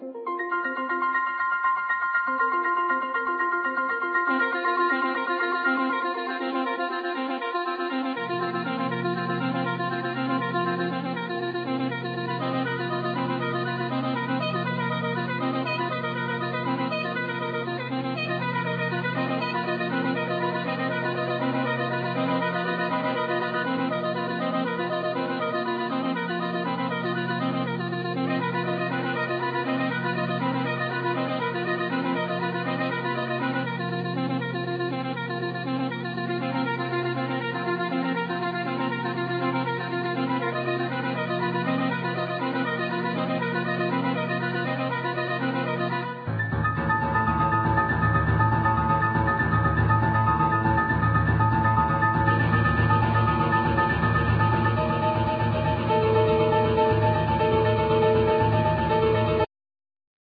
Keyboards
Bass
Guitar
Cello
Clarinet
Drums
Flute
Violin